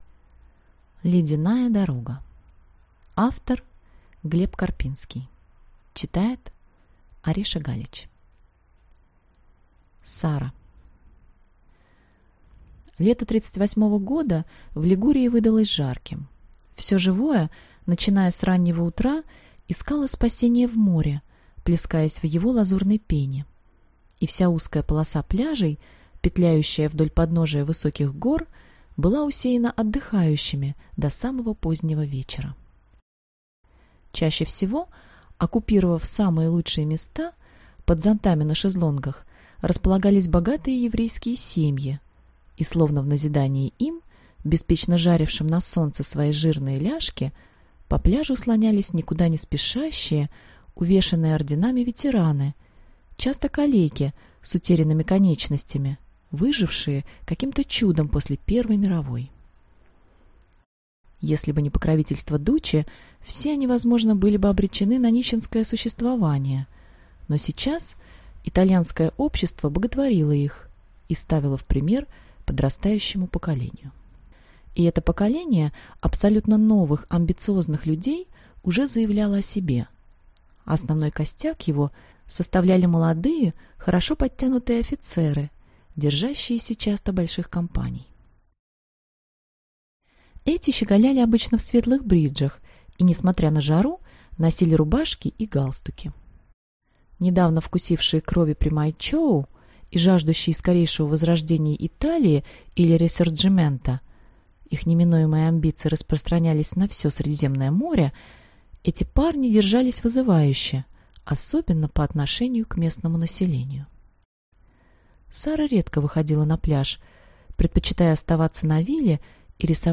Аудиокнига Ледяная дорога. Исторический роман | Библиотека аудиокниг